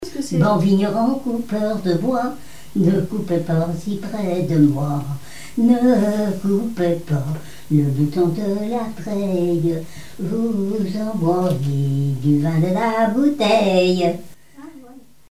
Mémoires et Patrimoines vivants - RaddO est une base de données d'archives iconographiques et sonores.
Genre strophique
Chansons et témoignages
Pièce musicale inédite